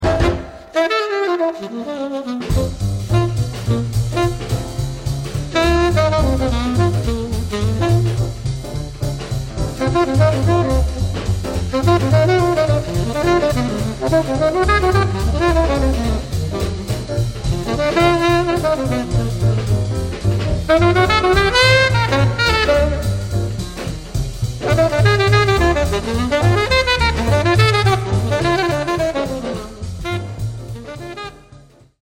Tenor Sax Solo